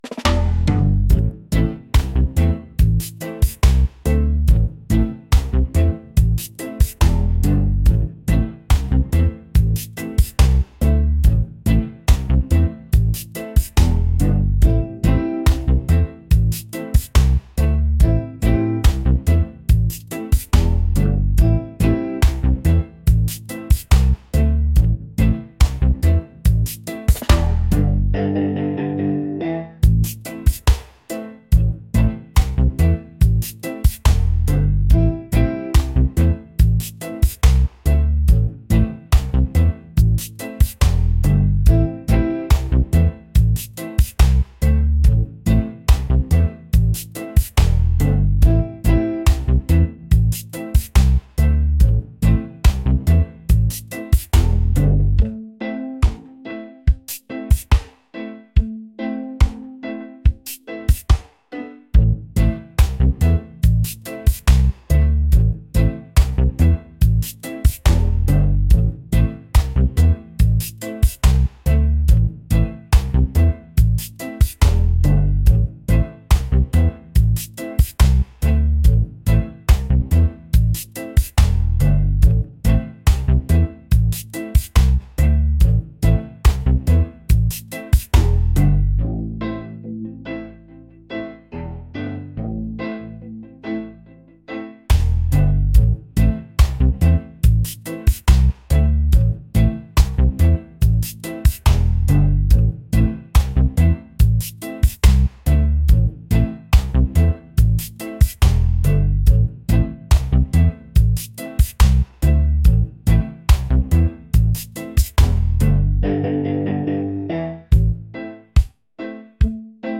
laid-back | reggae | island | vibes